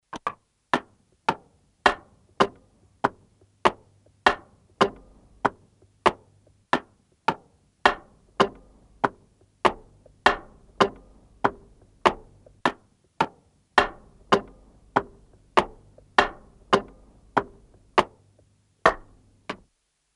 走-硬地面.mp3
通用动作/01人物/01移动状态/01硬地面/走-硬地面.mp3
• 声道 立體聲 (2ch)